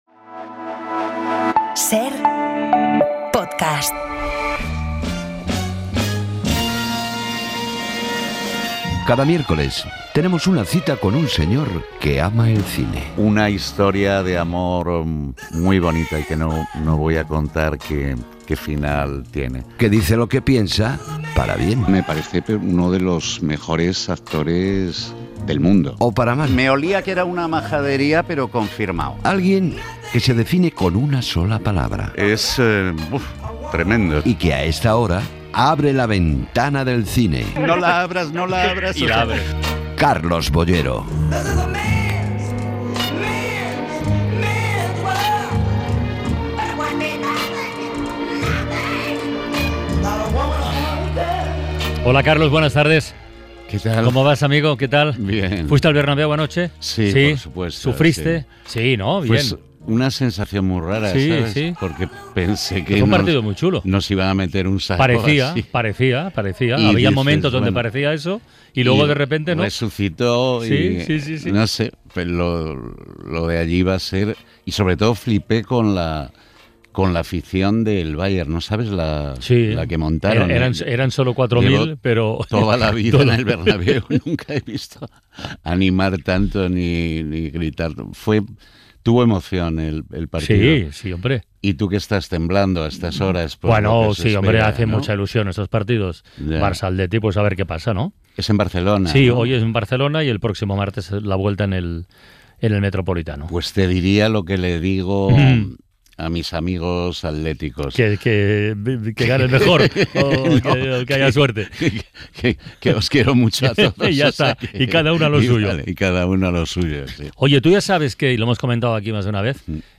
Nuestro crítico de cine Carlos Boyero visita 'La Ventana' para hablarnos de los nuevos estrenos que llegan a la cartelera esta semana.